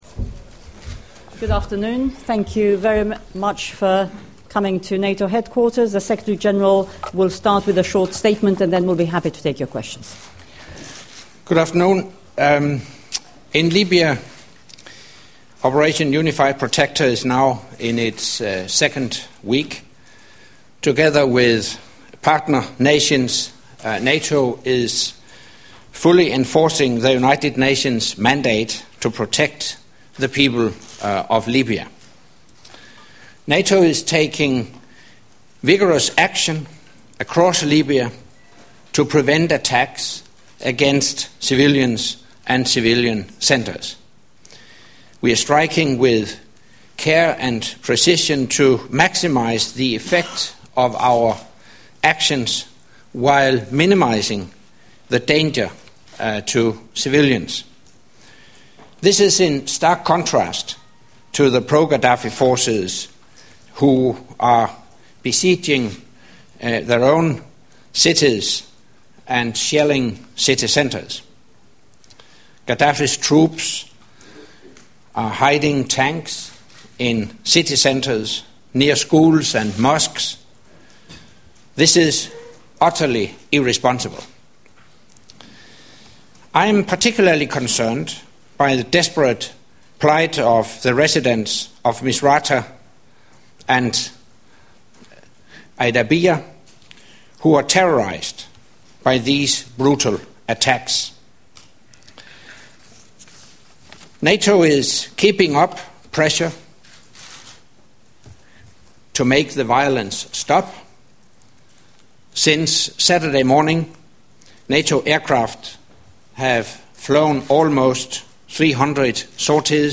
Audio Monthly press conference by NATO Secretary General Anders Fogh Rasmussen 11 Apr. 2011 | download mp3 From the event Transcript of the NATO Secretary General's monthly press conference 11 Apr. 2011 You need to install Flash.